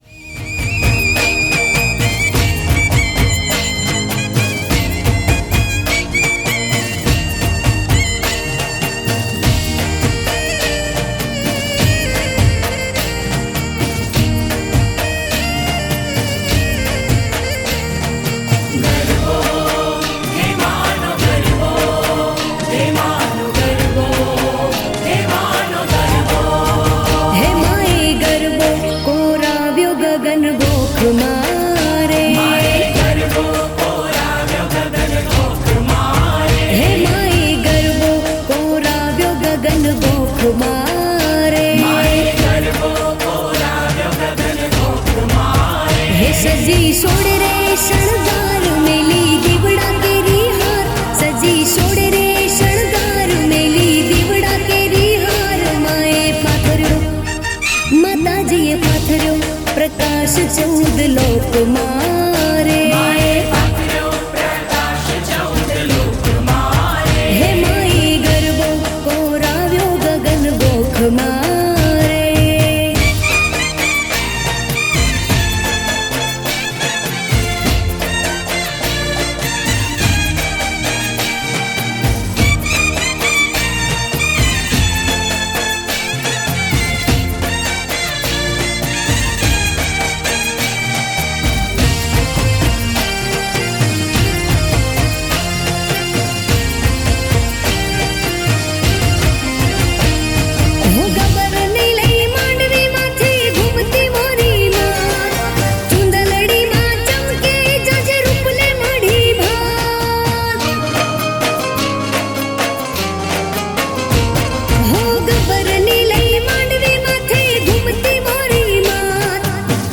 Gujarati Garba